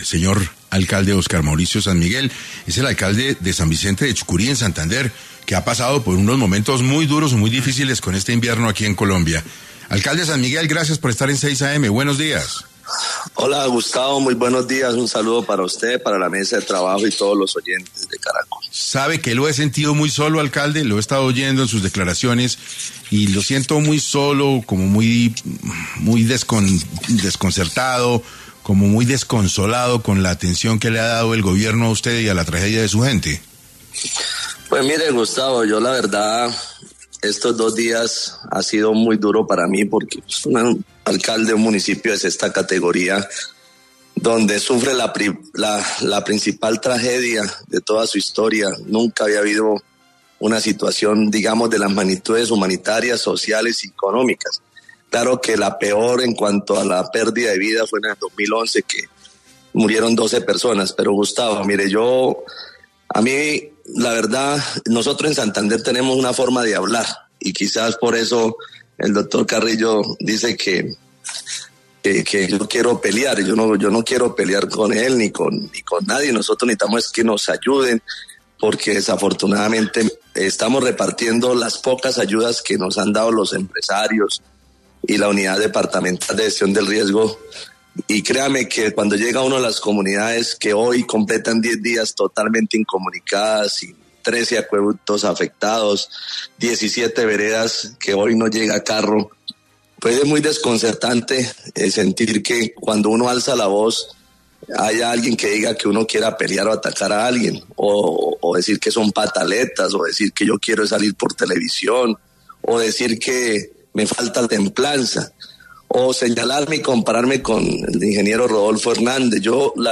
En Caracol Radio estuvo Óscar Mauricio Sanmiguel, alcalde de San Vicente de Chucurí, Santander, conversando sobre el desbordamiento de la quebrada Las Cruces.